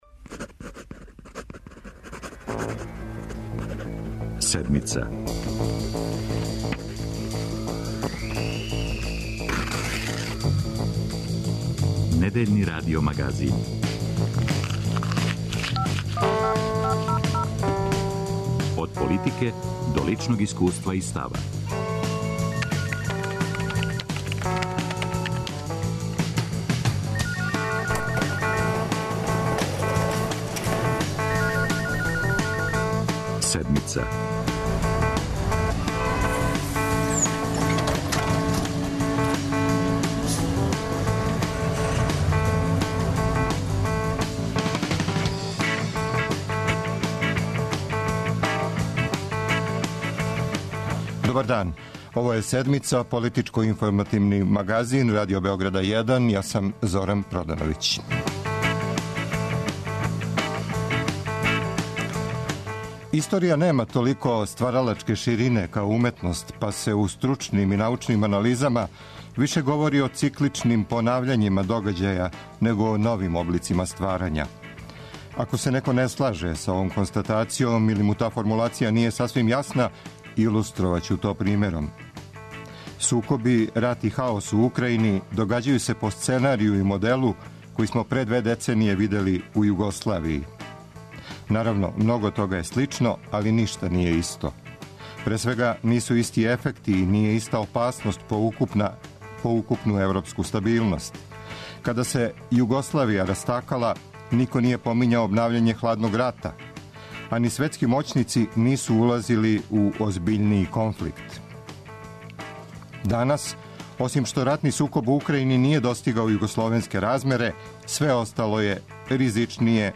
Украјински рулет са руским пуњењем. Избор између дипломатије и референдума. Србија за источно-западну варијанту подршке. За Седмицу говоре дипломате Душан Батаковић, Владета Јанковић и Душан Спасојевић.